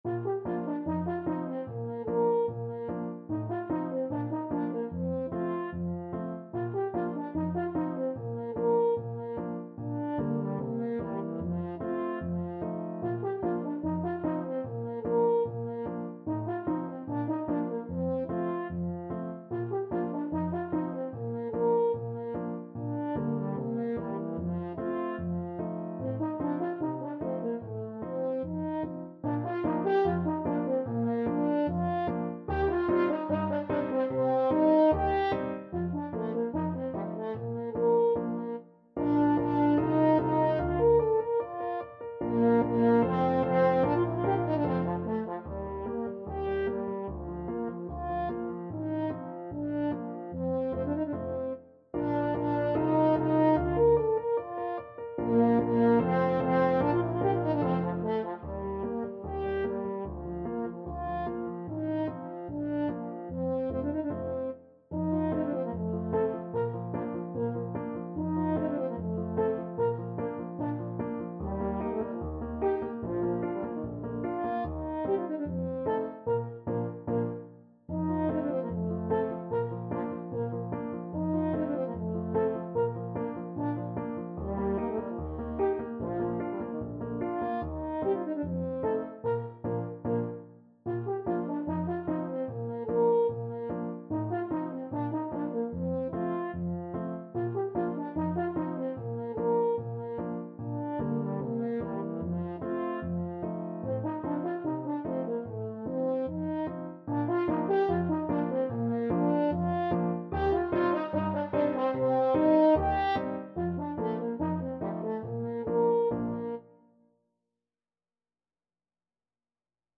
French Horn
Allegretto = 74